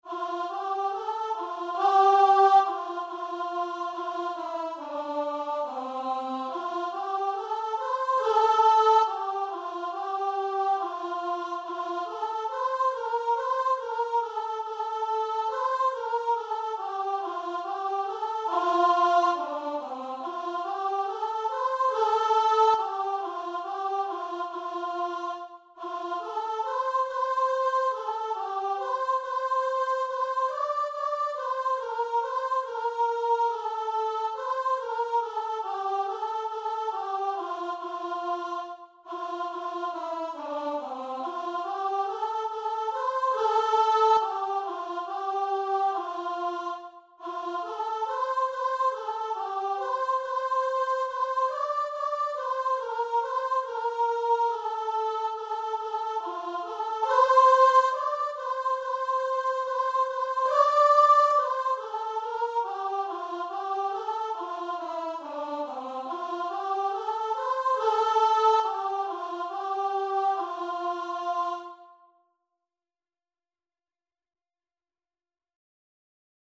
Voice version
4/4 (View more 4/4 Music)
C5-D6
Christian (View more Christian Voice Music)